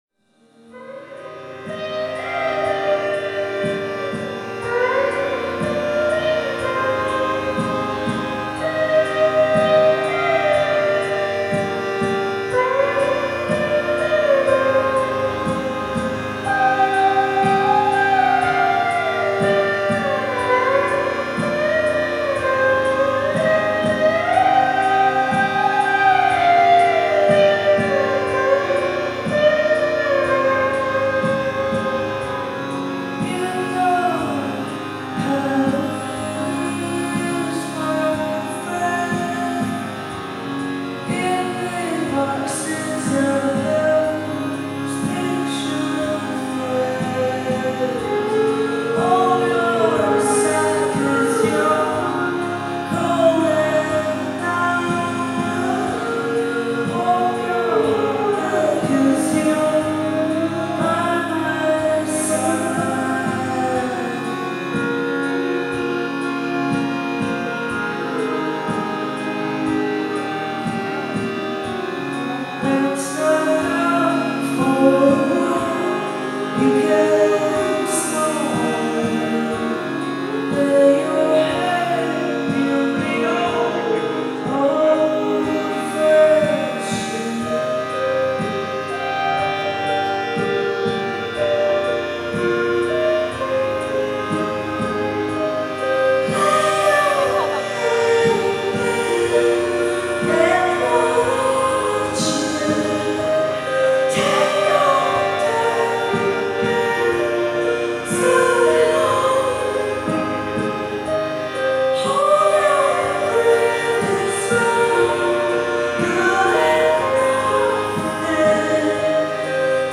Live at the Museum of Fine Arts
in Boston, Massachusetts